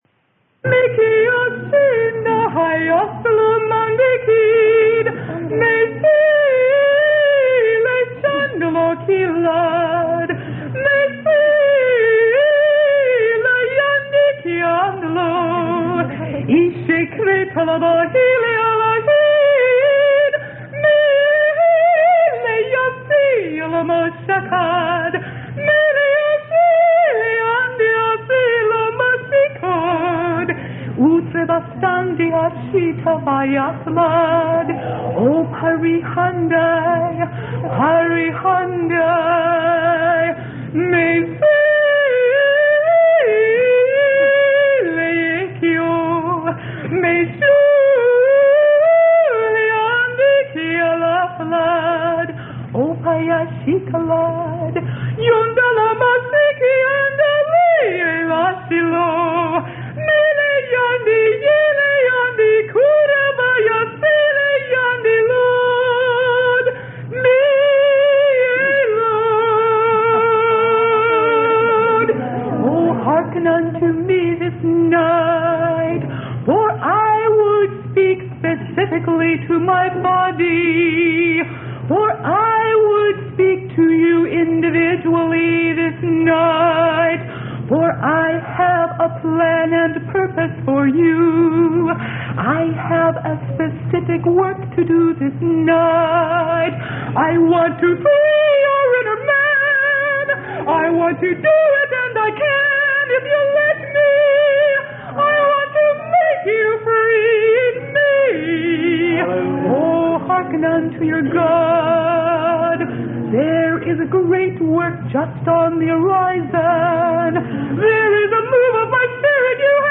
Sermon: God'S Call To Freedom.